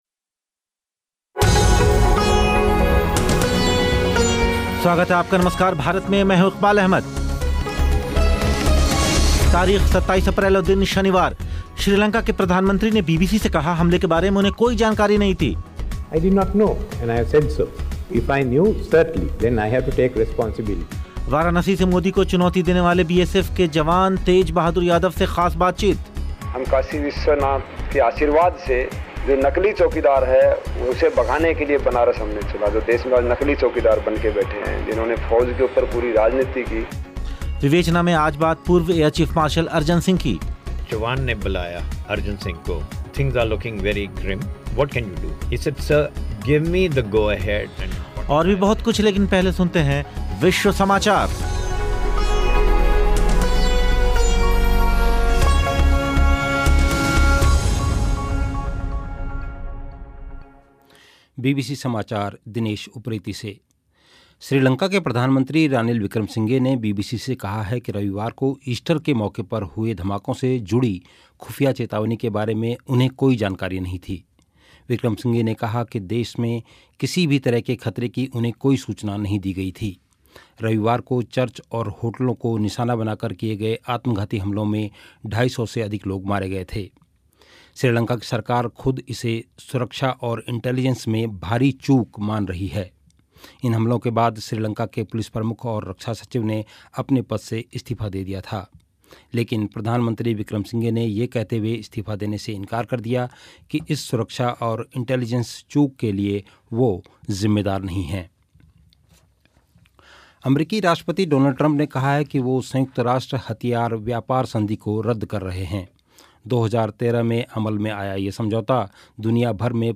ख़ास बातचीत